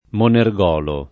monergolo [ moner g0 lo ]